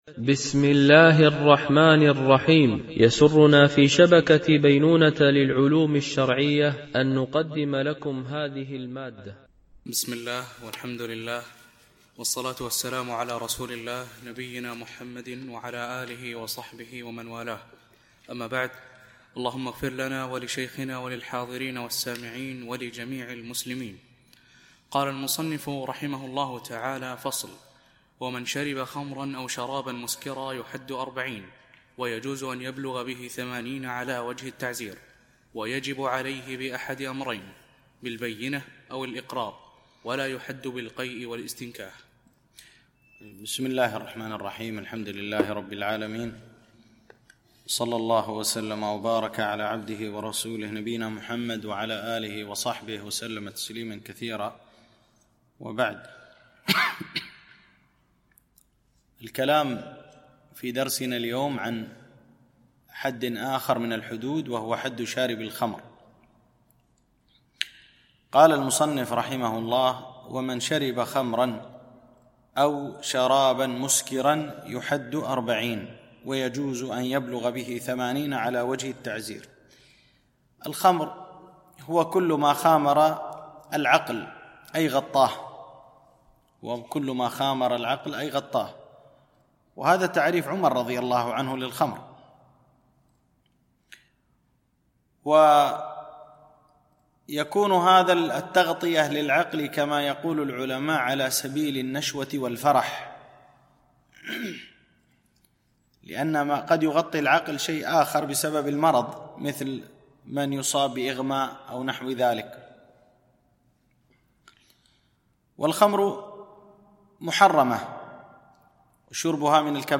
التنسيق: MP3 Mono 44kHz 64Kbps (CBR)